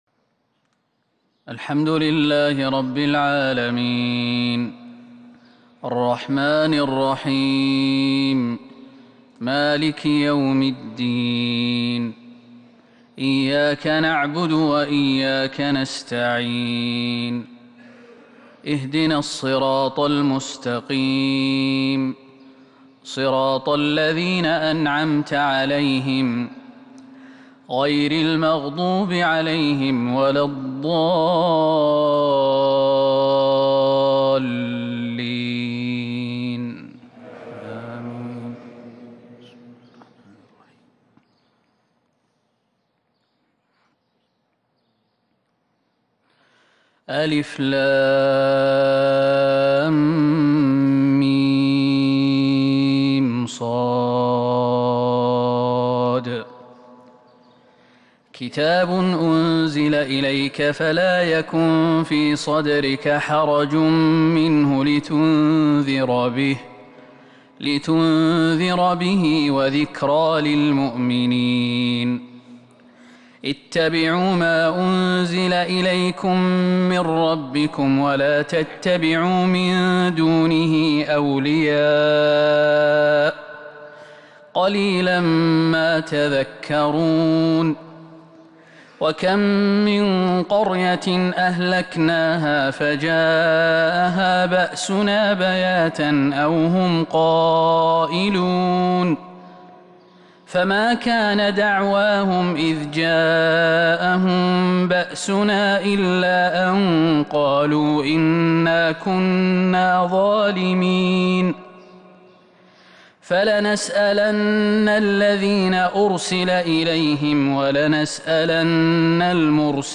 فجر الأربعاء 7-6-1442 هــ من سورة الأعراف | Fajr prayer from Surat Al-A'raaf 20/1/2021 > 1442 🕌 > الفروض - تلاوات الحرمين